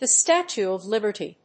アクセントthe Státue of Líberty